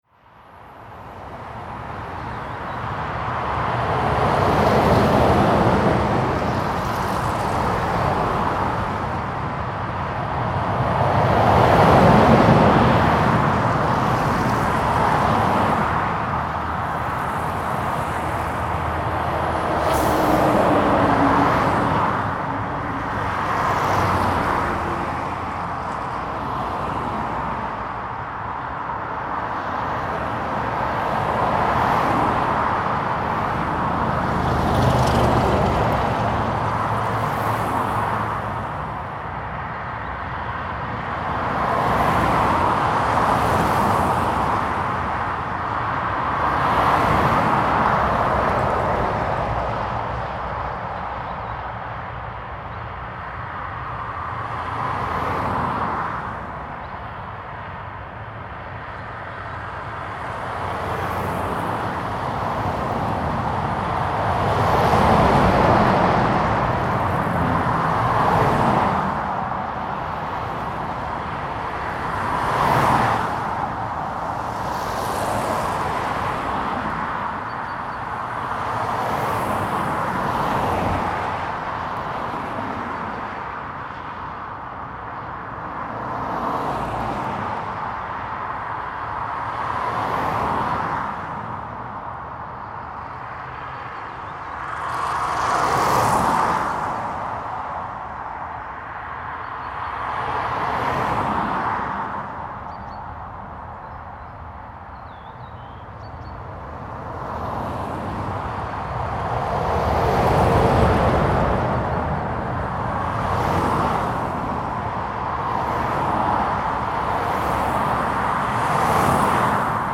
The video shows the road of Pyhäjärventie connecting Nokia to Linnainmaa, passing through the Pyhäjärvi lake.
• cars
• Highway